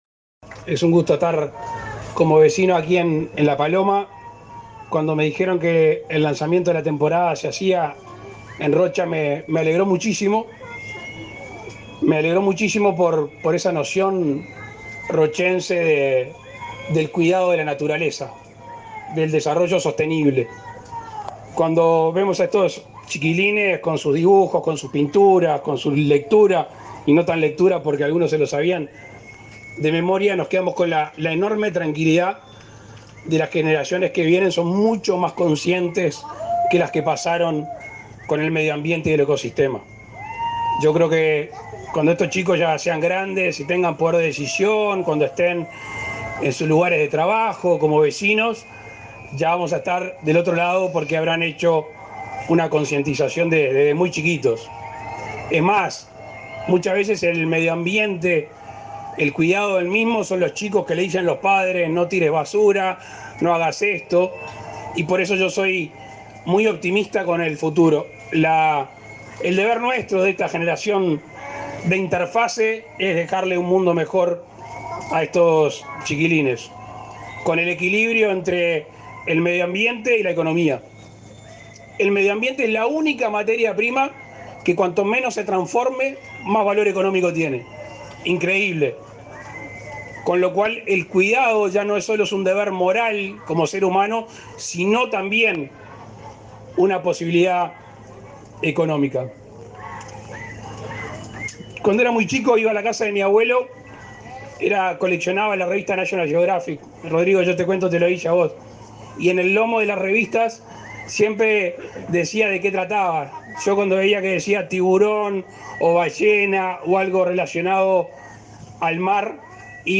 Palabras del presidente Lacalle Pou
Palabras del presidente Lacalle Pou 29/08/2022 Compartir Facebook X Copiar enlace WhatsApp LinkedIn El presidente Luis Lacalle Pou encabezó el acto de lanzamiento de la temporada de avistamiento de ballenas en el balneario La Paloma, departamento de Rocha.